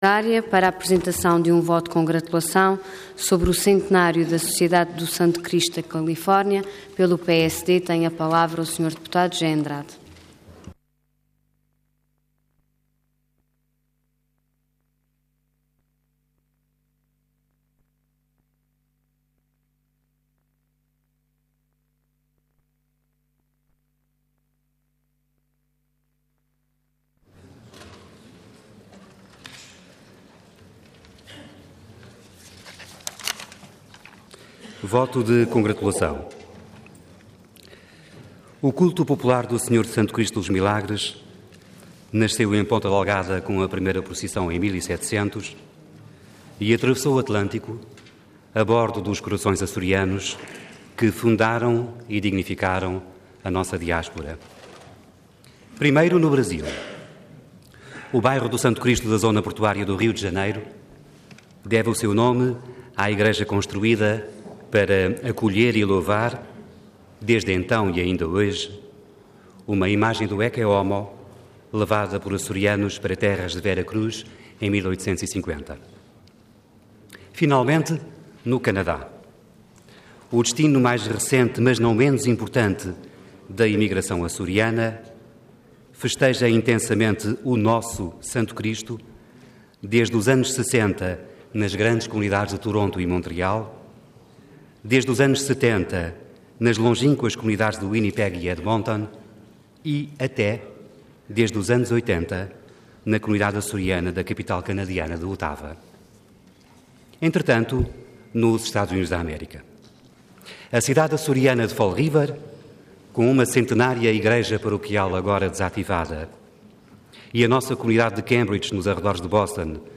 Detalhe de vídeo 15 de maio de 2013 Download áudio Download vídeo Diário da Sessão Processo X Legislatura Centenário da Sociedade do Santo Cristo da Califórnia. Intervenção Voto de Congratulação Orador José Andrade Cargo Deputado Entidade PSD